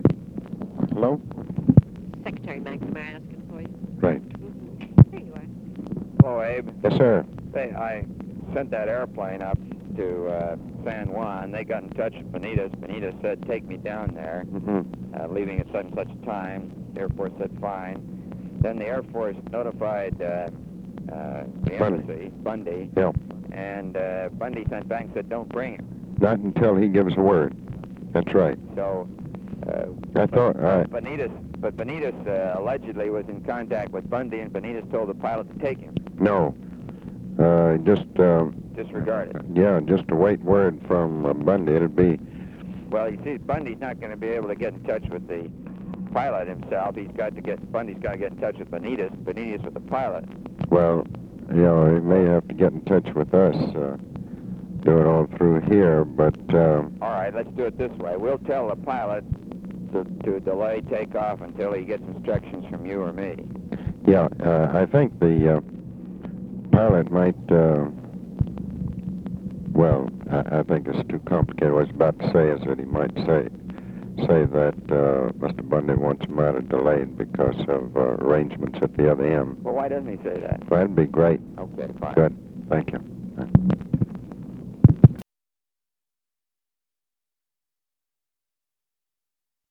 Conversation with ROBERT MCNAMARA and ABE FORTAS
Secret White House Tapes